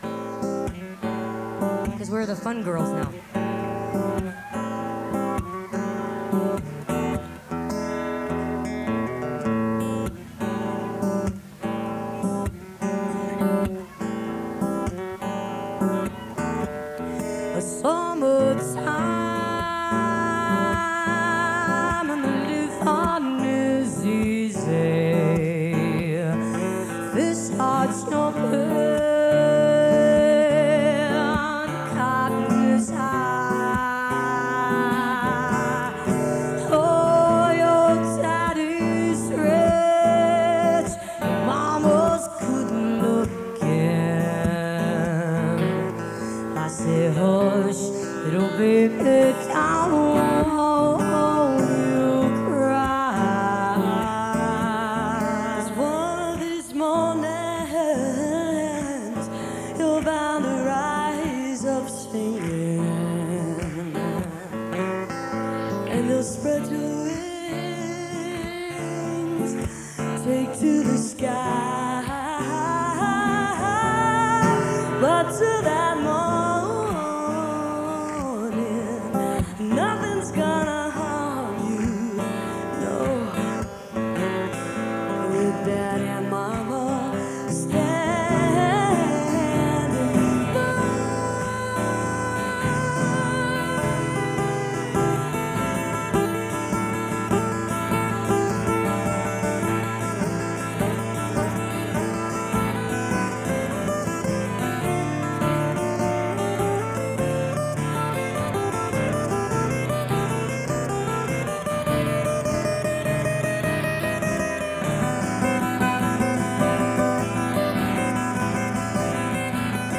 (radio broadcast source)